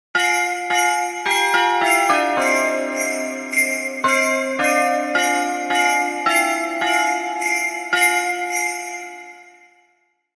Festive Christmas Doorbell Melody
A warm and cheerful Christmas melody doorbell sound featuring festive bells, sleigh bells, and a bright holiday atmosphere. Perfect for seasonal videos, intros, or notifications, with a pleasant ding-dong tone inspired by Ding Dong Merrily on High Listen and download in MP3 format.
Genres: Sound Effects
Festive-christmas-doorbell-melody.mp3